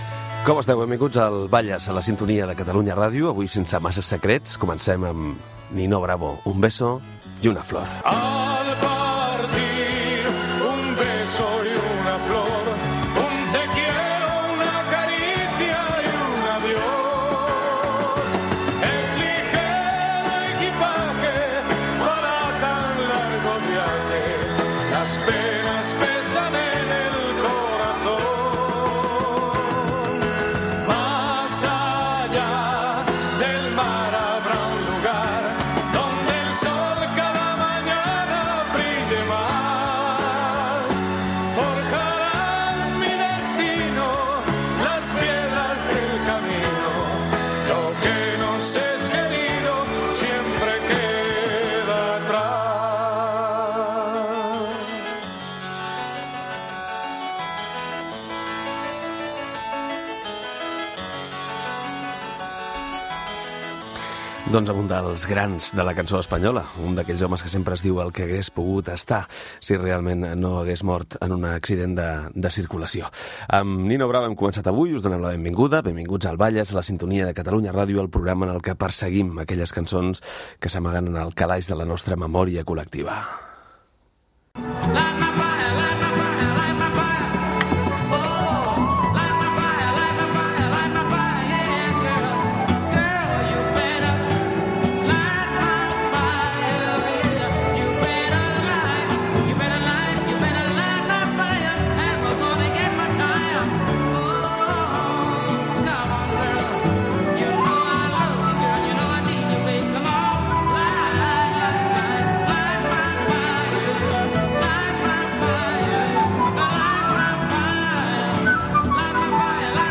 Benvinguda, tema musical, comentari, tema musical i comentari
Musical